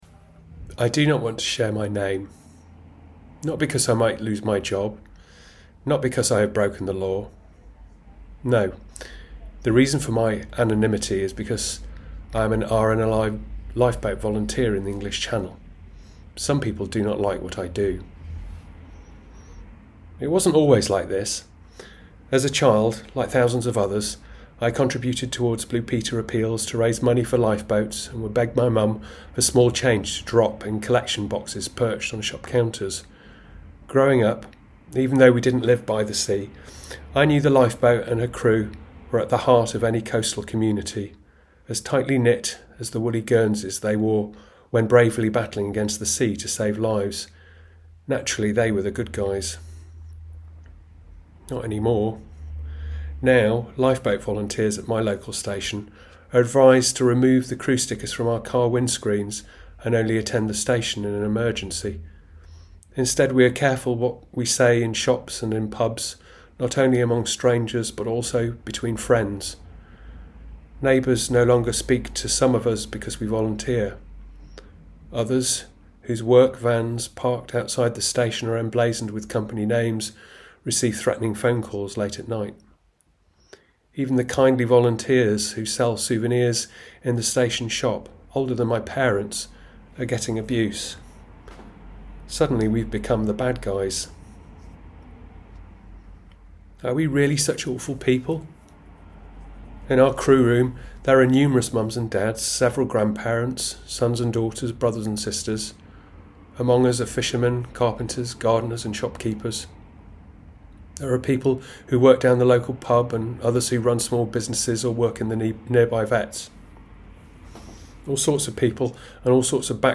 Listen: an RNLI volunteer describes the shocking reality of migrant rescues in the English Channel - 19/10/2022